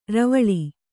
♪ ravaḷi